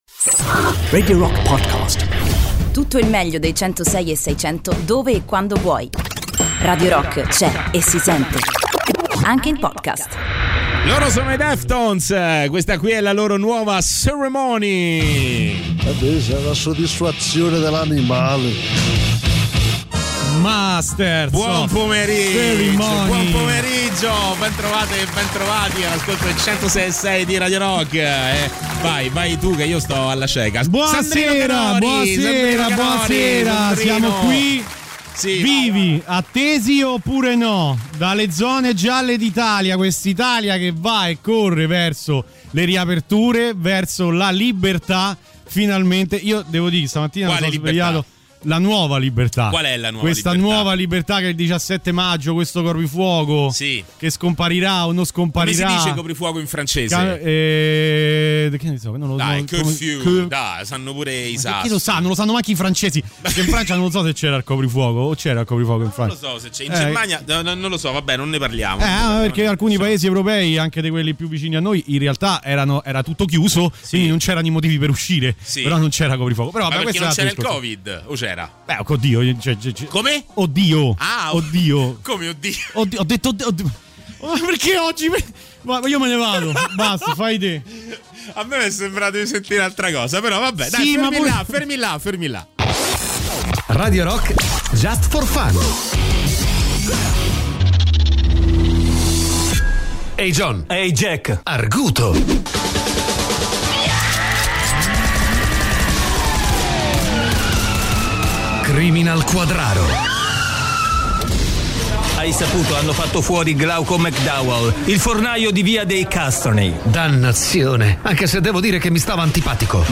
in diretta sui 106.6 di Radio Rock dal Lunedì al Venerdì dalle 17.00 alle 19.00.